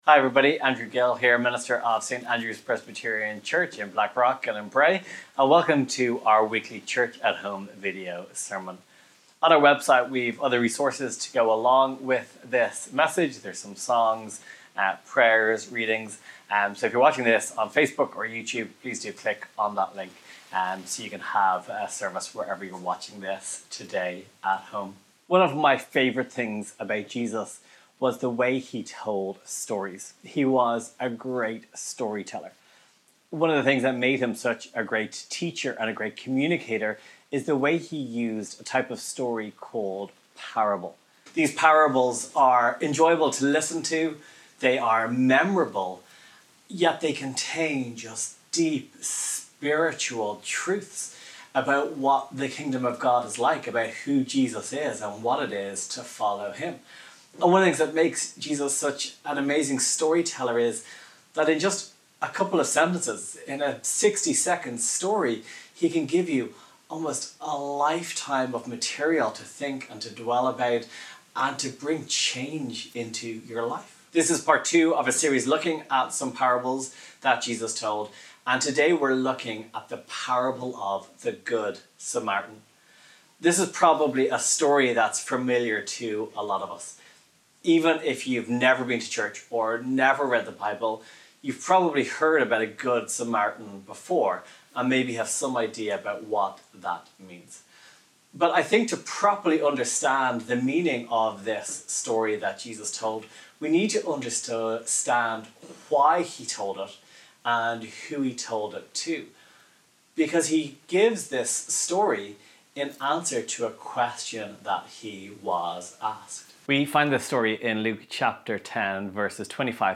Today we continue our new sermon series looking at the Parables of Jesus.